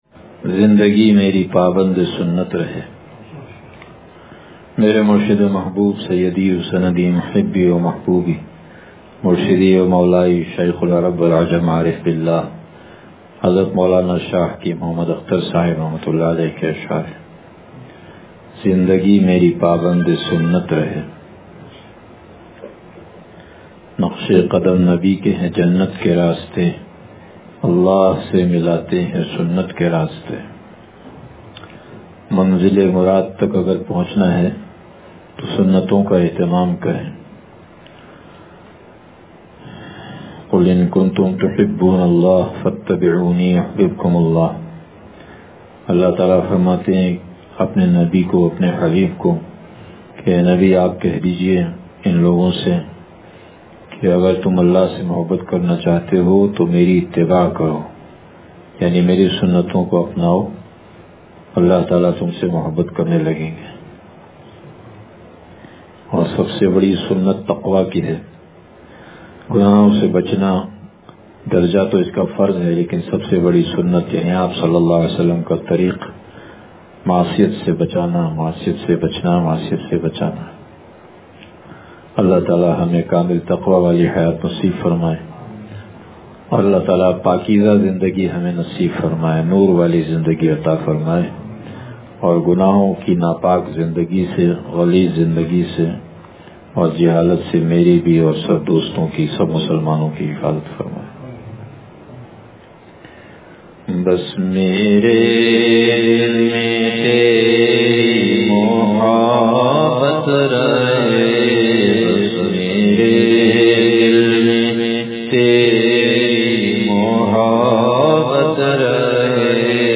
زندگی میری پابندِ سنت رہے – مجلس بروز بدھ